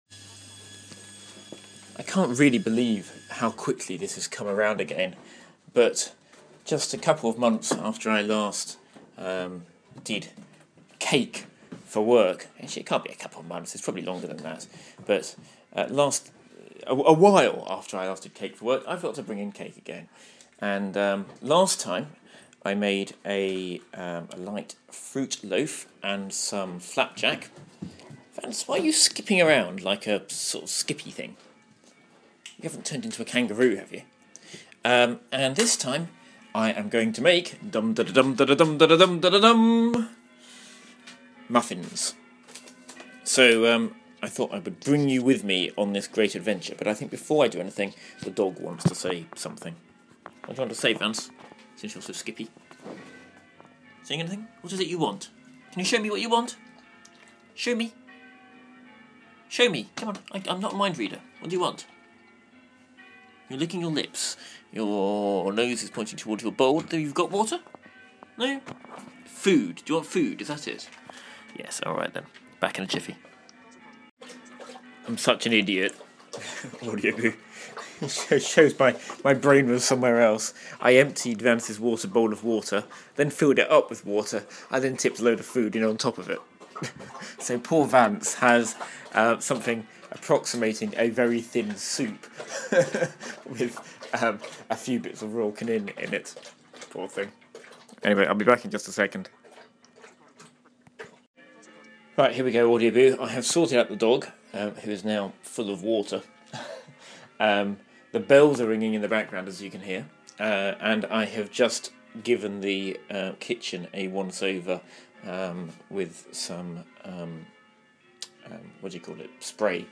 So I'm setting out on an adventure to cook myself, for the very first time, some muffins. Listen to this boo as I measure out liquid using a beer glass and way flour with scales that magically gain weight.